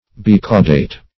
Bicaudate \Bi*cau"date\
bicaudate.mp3